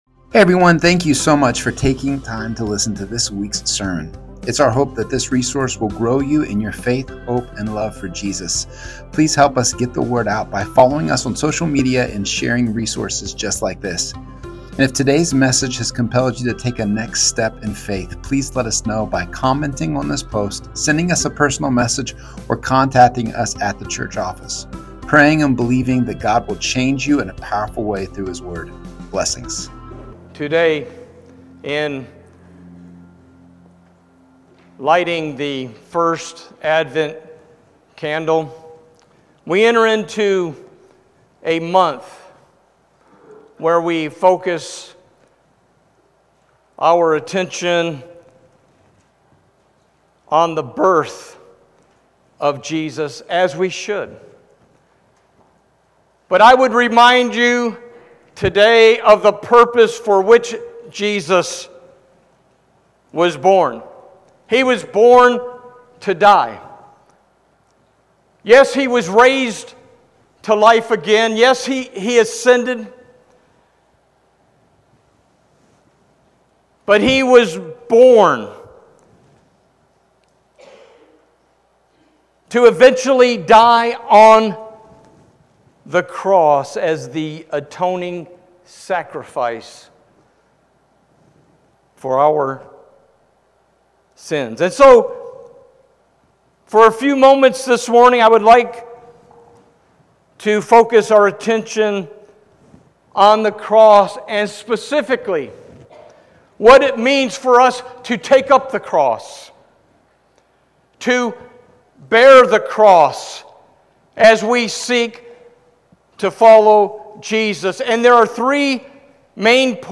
Sermons | First Baptist Church of St Marys